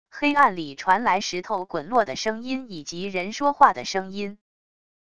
黑暗里传来石头滚落的声音以及人说话的声音wav音频